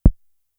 Index of /90_sSampleCDs/300 Drum Machines/Fricke Schlagzwerg/Kicks
Kick (1).WAV